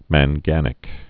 (măn-gănĭk, măng-)